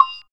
Percs
PERC.32.NEPT.wav